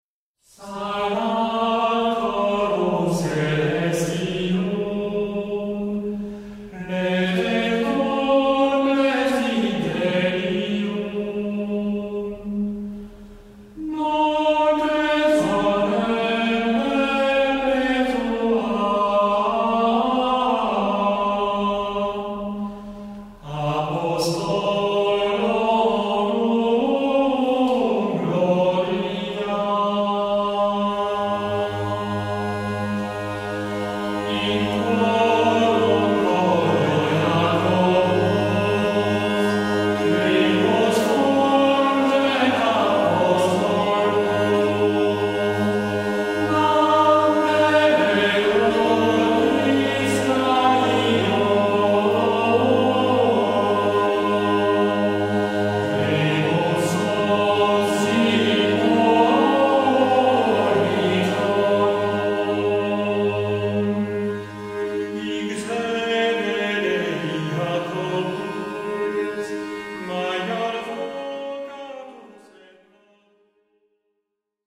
Ces disques traduisent l’esprit vivant du sanctuaire : les pierres ne sont pas seulement statiques mais font résonner les voix et les instruments.
Ensemble FULBERT
Cet enregistrement réalisé en 2006 en la crypte de la cathédrale, avec instruments anciens, reprend les grands textes de Fulbert : De Philomela, Stirps Jesse et Solem justifiae (répons pour la fête de la Nativité de la Vierge Marie), Chorus novae (hymne), etc…
Hymnes en conduits instrumentaux.